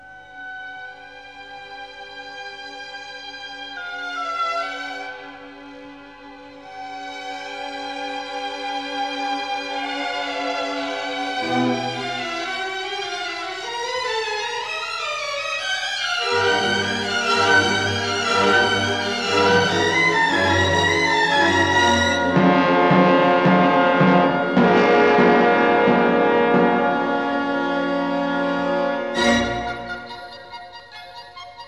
Stereo recording made in July 1959 at the
Walthamstow Assembly Hall, London